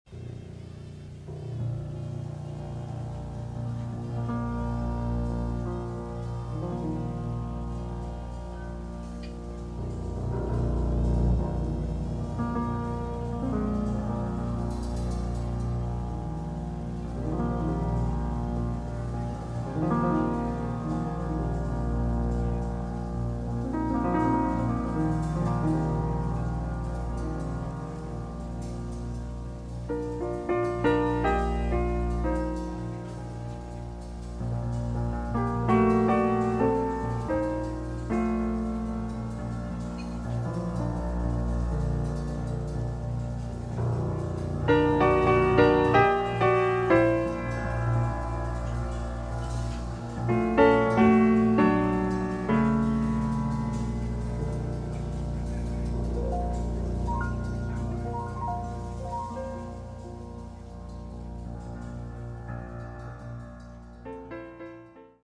Acoustic and electric pianos
Tenor and soprano saxophones
Acoustic bass
Drums
Recorded live at George's, Toronto, October 1975